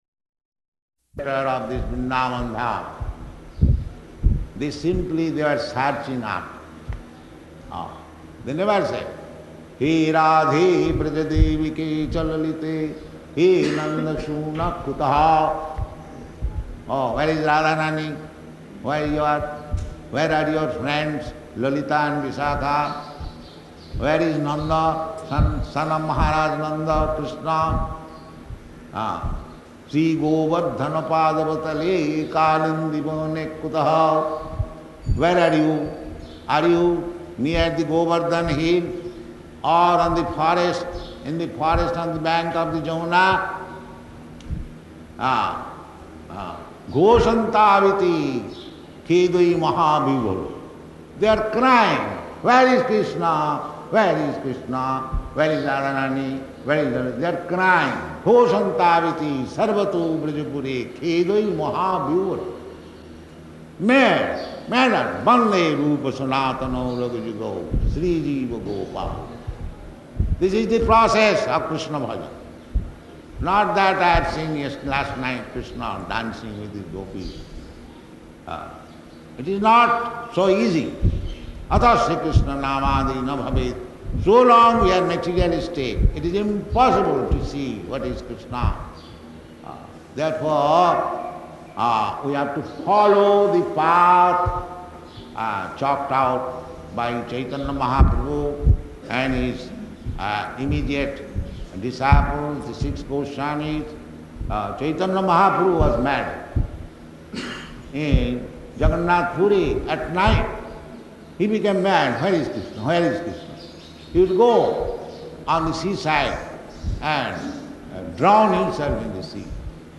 Śrīmad-Bhāgavatam 1.5.31, partially recorded
Location: Vṛndāvana